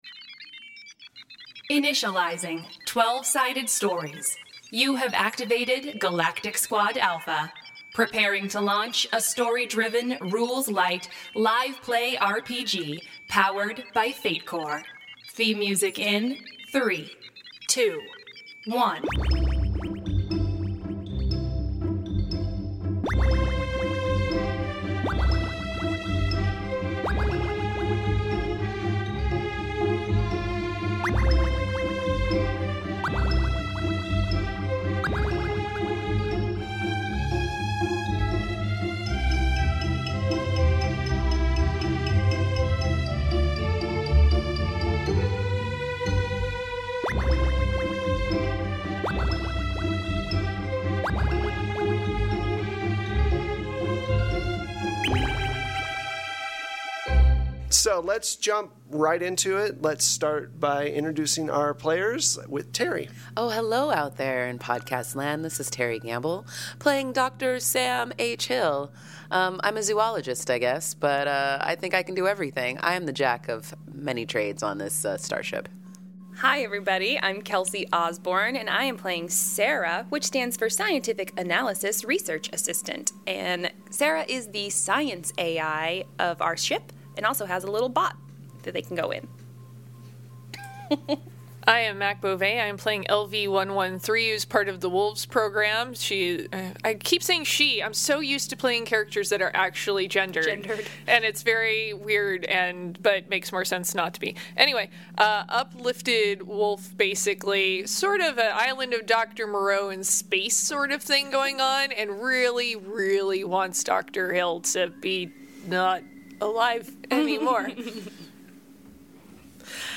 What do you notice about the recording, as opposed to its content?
Twelve-Sided Stories is archiving it's past Actual Play Content to of our main RSS feed.